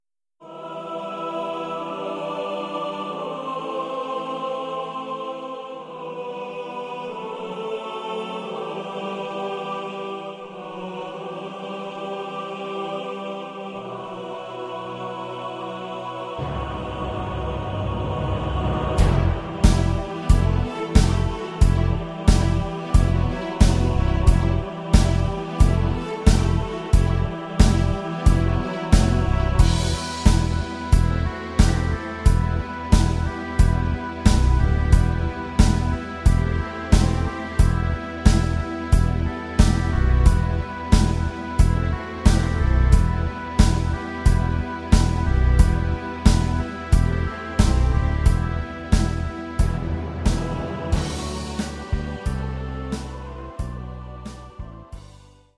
avec 300 choristes